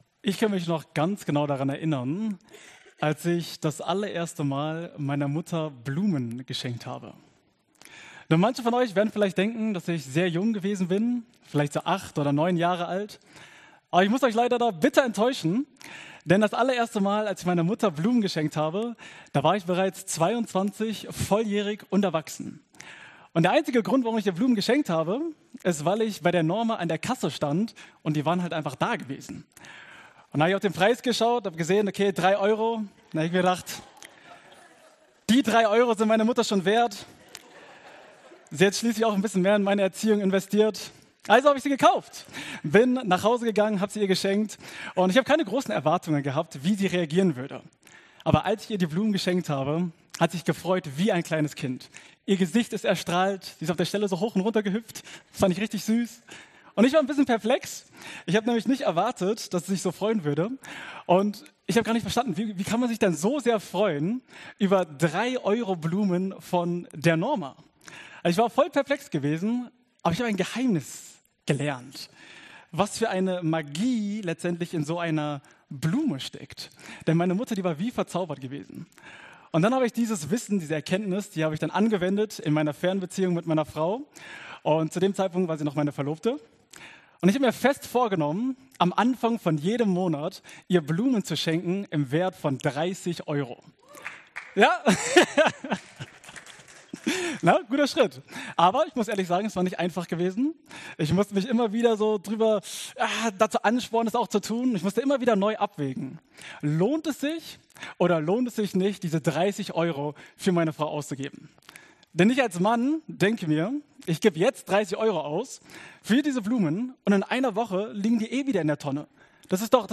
Predigt-Zusammenfassung